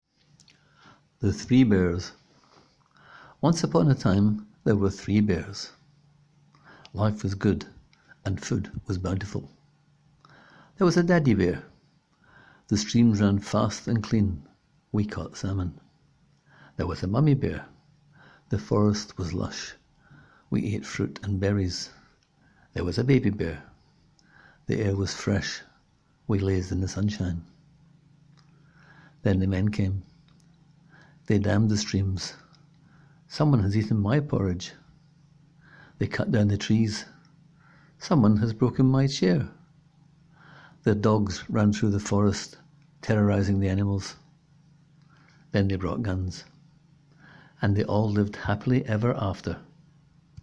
Click here to hear this 1-minute story read aloud by the writer: